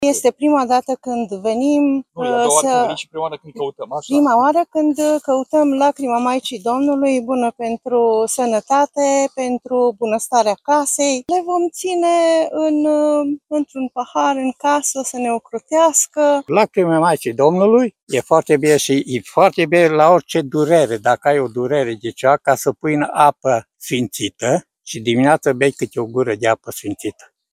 Sute de credincioși catolici s-au adunat la Basilica Papală Maria Radna, în județul Arad, pentru a sărbători Adormirea Maicii Domnului.
voxuri-Maria-Radna.mp3